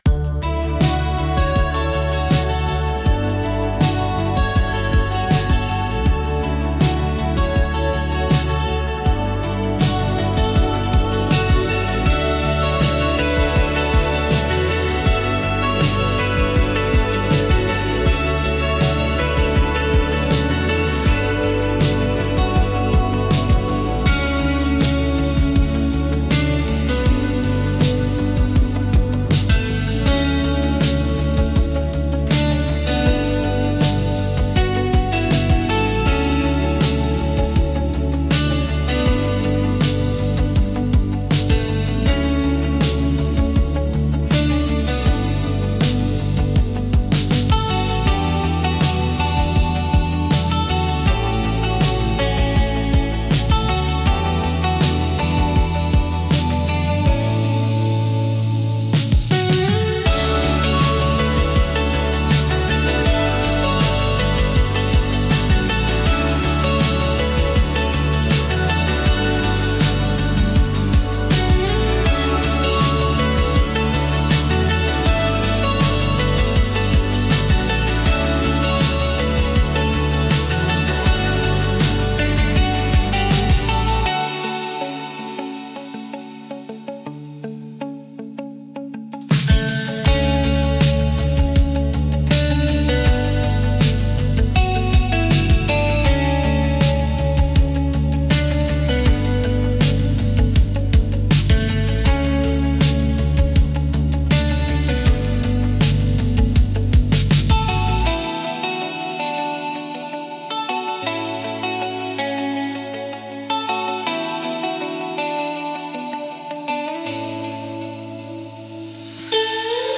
静かで美しい音の重なりが心地よく、感性を刺激しながらも邪魔にならない背景音楽として活用されます。